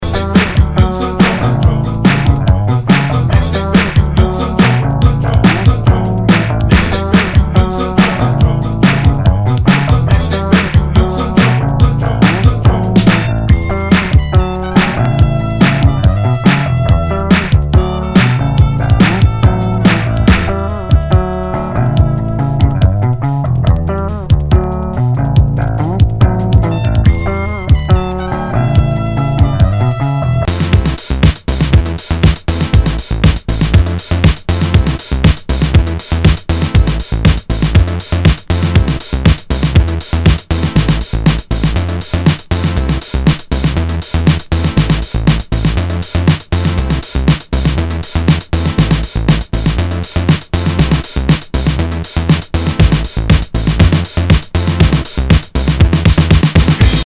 Nu- Jazz/BREAK BEATS
ナイス！ファンキー・ブレイクビーツ！